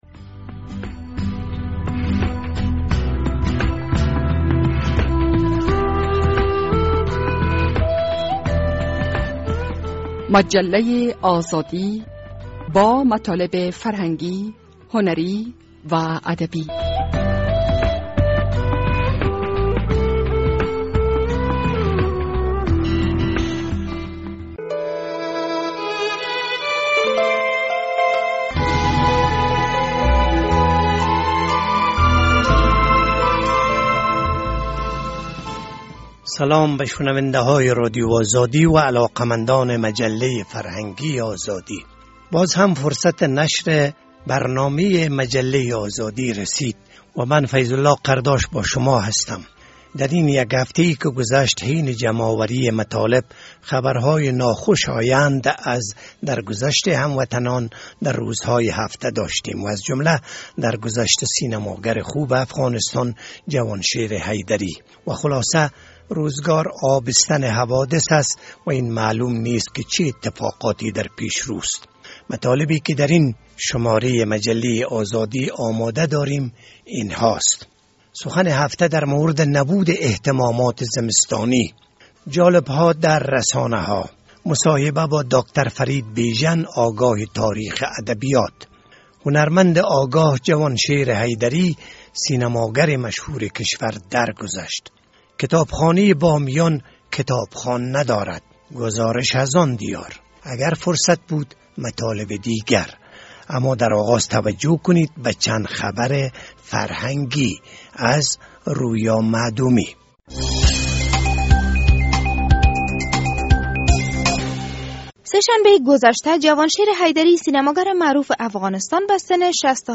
سخن هفته در مورد نبود اهتمامات زمستانی، داستان هفته، مصاحبه